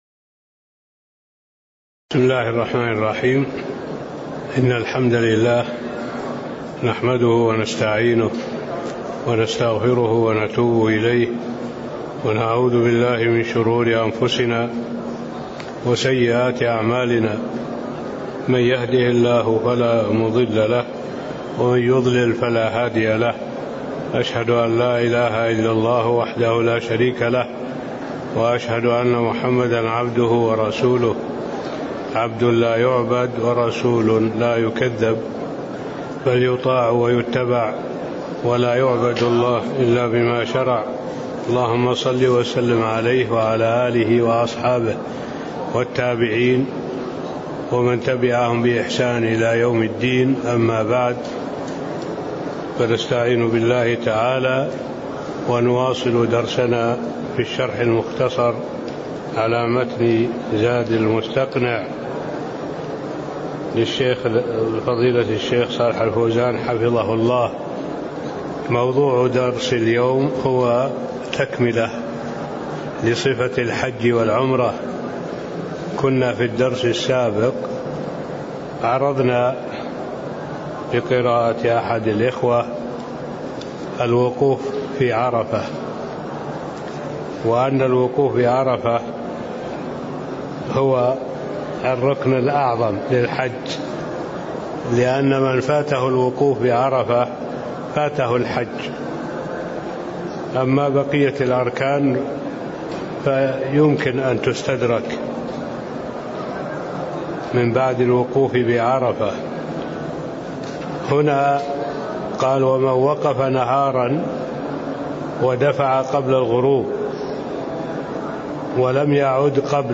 تاريخ النشر ٢٥ شوال ١٤٣٤ هـ المكان: المسجد النبوي الشيخ